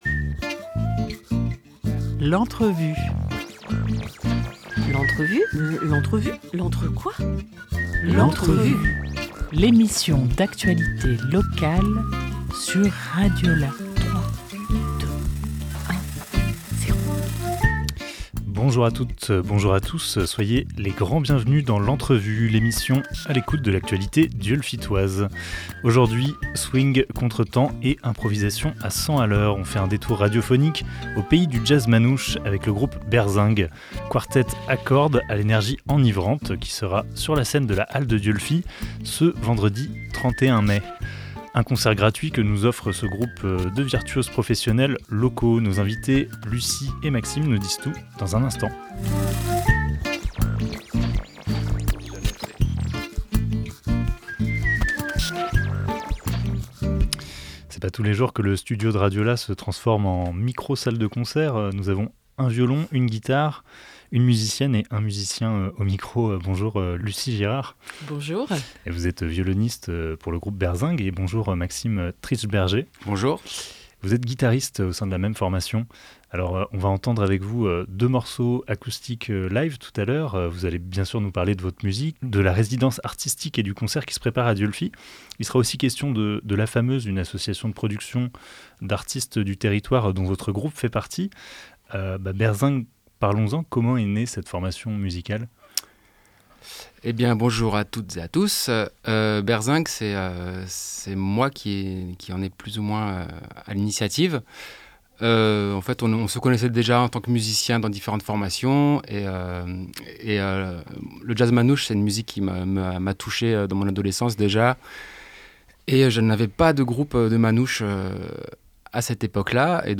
Jazz, swing et session live au studio de RadioLà !